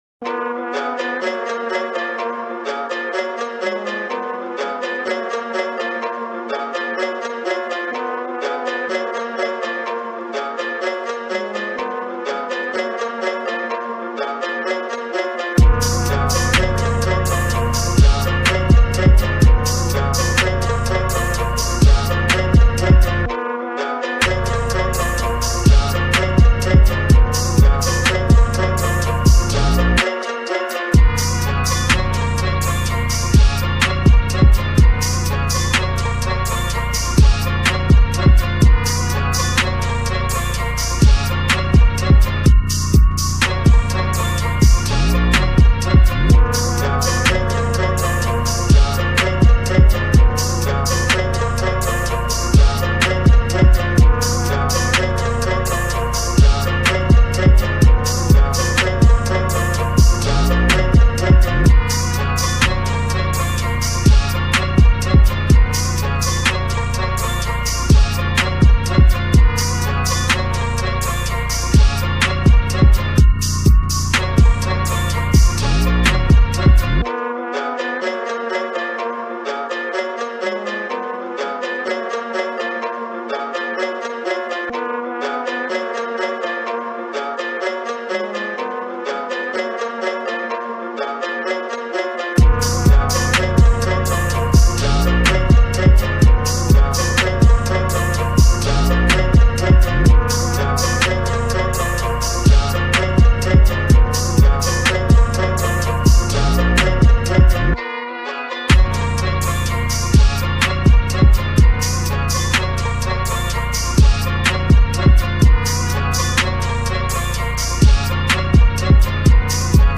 on September 12, 2022 in Rap Instrumental Archives